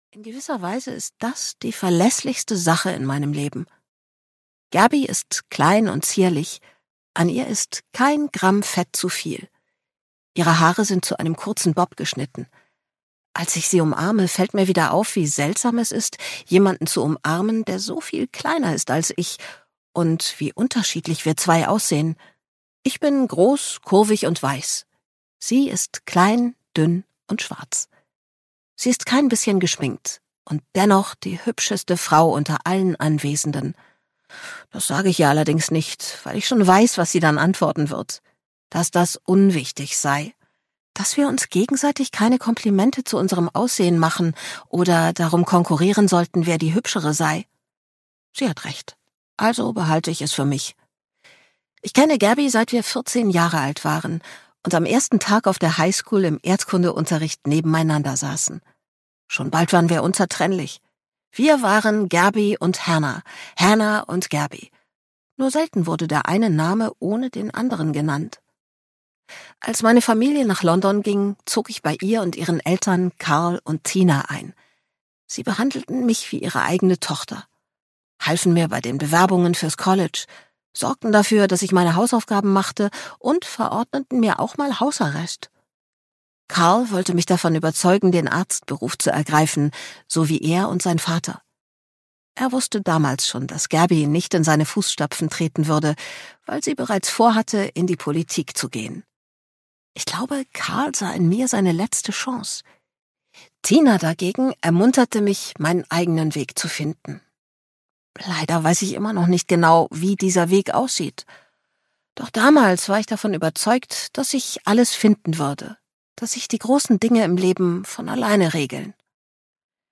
Maybe In Another Life (DE) audiokniha
Ukázka z knihy